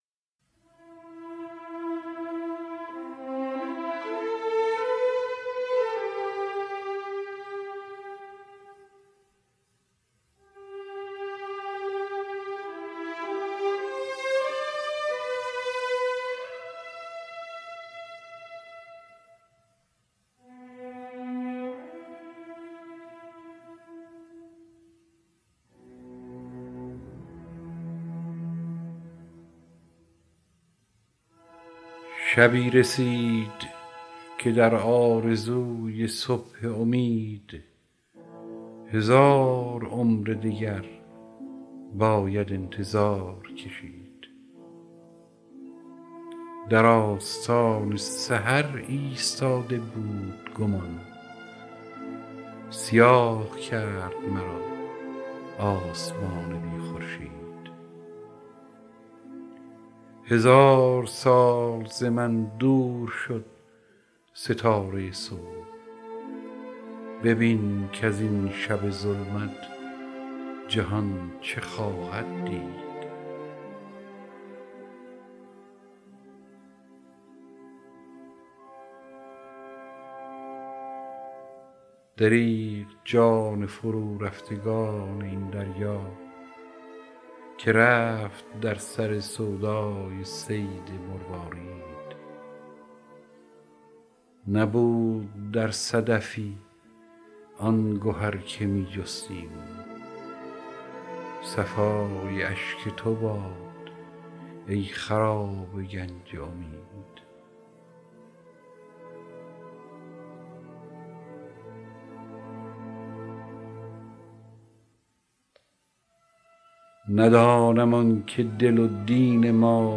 دانلود دکلمه سیاه و سپید با صدای هوشنگ ابتهاج همراه با متن
گوینده :   [هوشنگ ابتهاج]